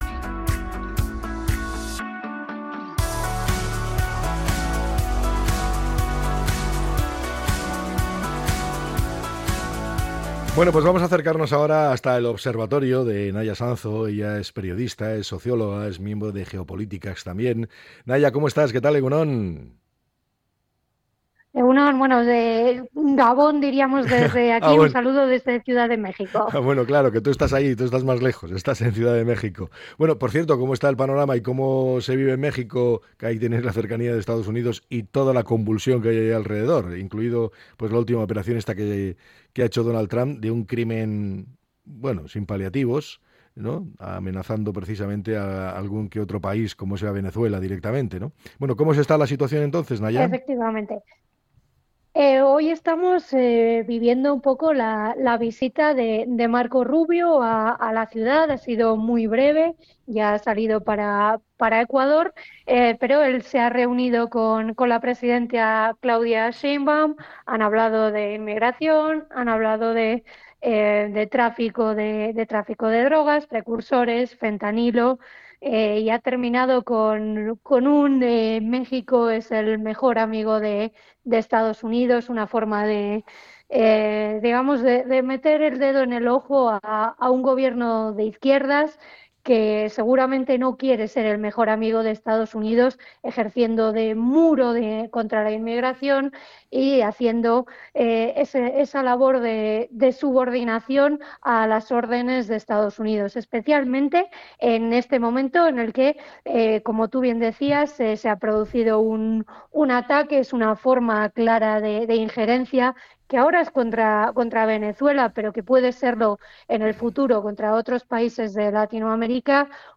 Trump, migración y Gaza: las claves de la entrevista